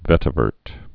(vĕtə-vûrt)